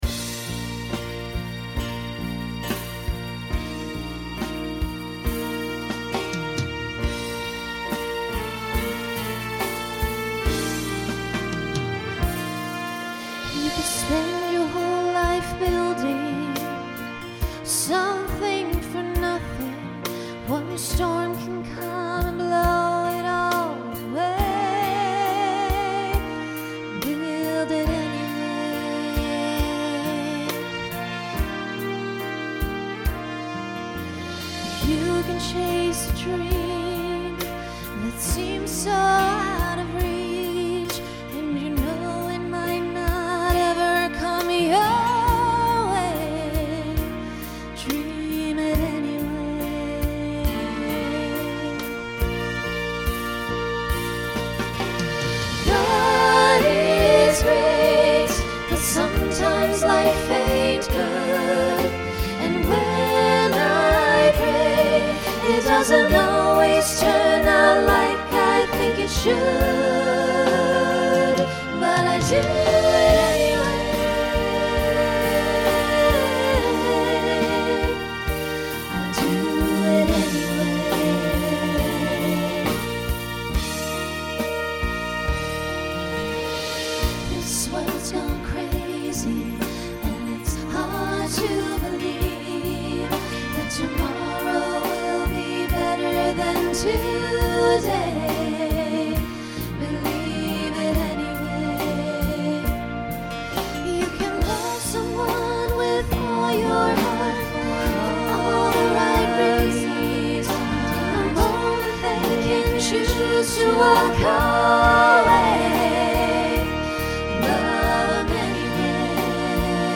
Voicing SATB Instrumental combo Genre Pop/Dance
Show Function Ballad